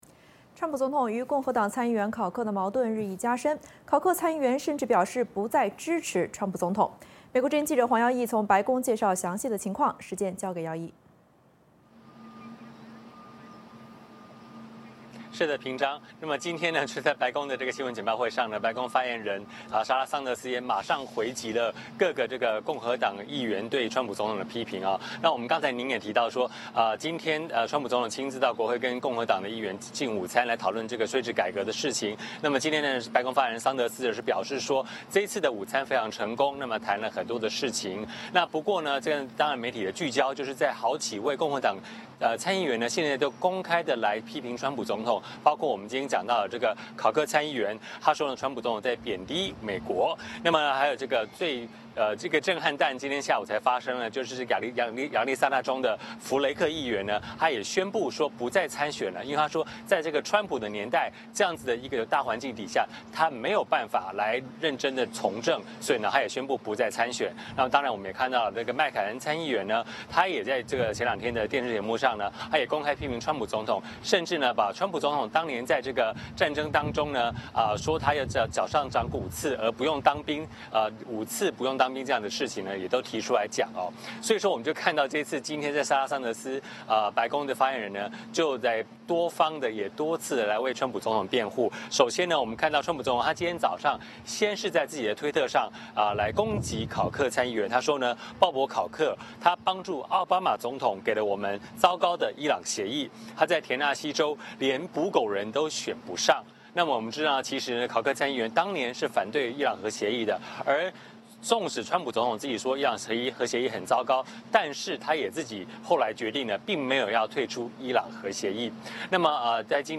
VOA连线：川普攻击共和党议员“连捕狗人都选不上”